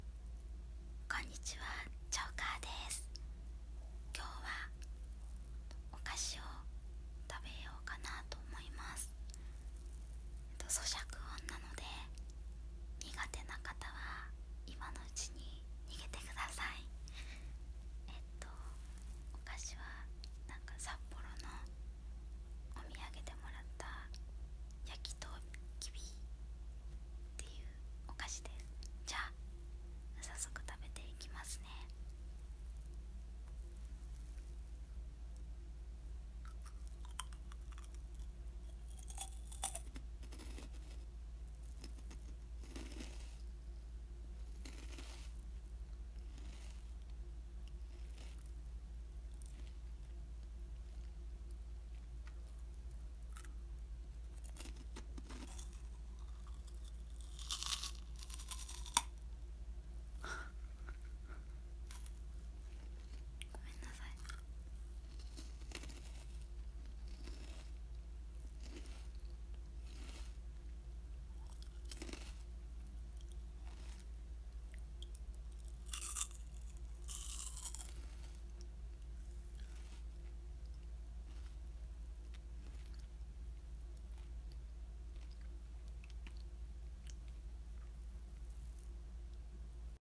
音フェチ★おかき